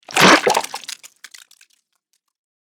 horror
Gore Cartoon Flesh Rip Impact with Splatter